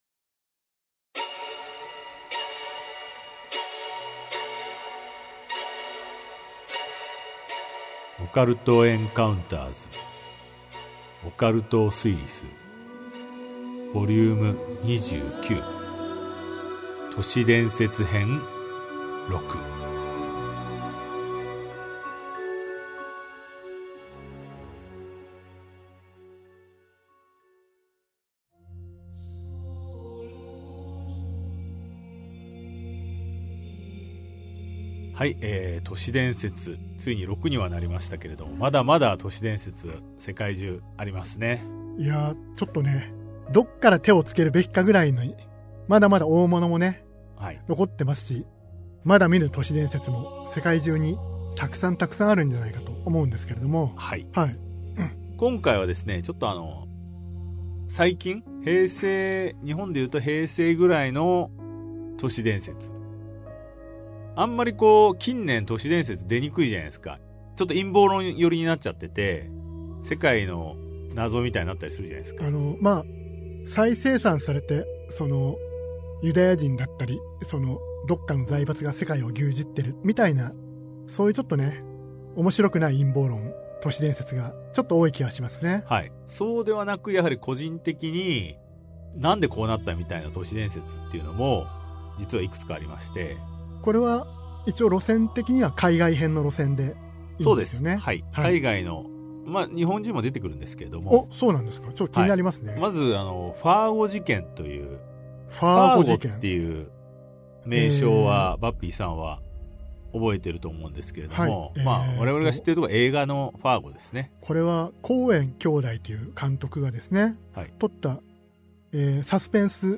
[オーディオブック] オカルト・エンカウンターズ オカルトを推理する Vol.29 都市伝説 6